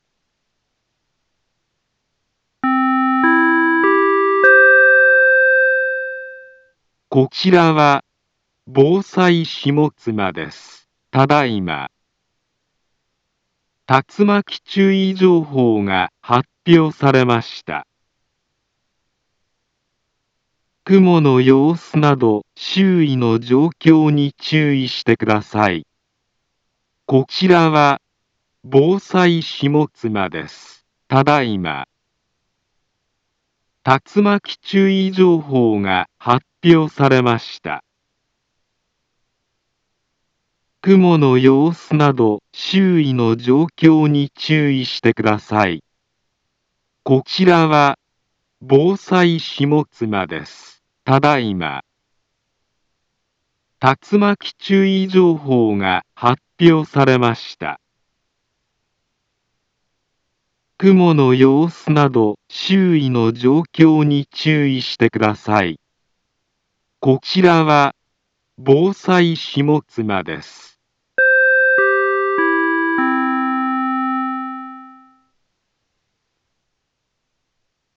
Back Home Ｊアラート情報 音声放送 再生 災害情報 カテゴリ：J-ALERT 登録日時：2023-07-10 22:25:06 インフォメーション：茨城県南部は、竜巻などの激しい突風が発生しやすい気象状況になっています。